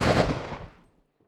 AssaultCanon_1p_tail.wav